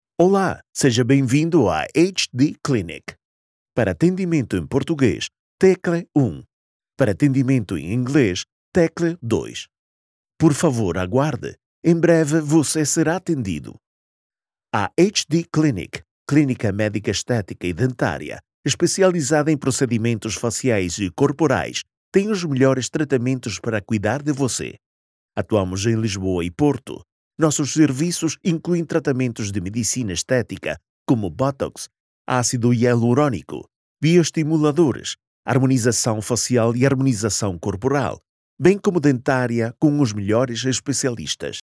Commercial, Distinctive, Accessible, Versatile, Reliable
Telephony
His voice is often described as fresh, young, calm, warm, and friendly — the “guy next door” — ideal for commercials, audiobooks, narration, promos, YouTube and educational content, e-learning, presentations, and podcasts.
As a full-time producer, studio owner, and musician, he ensures clean, echo-free, uncompressed audio, delivered in any format.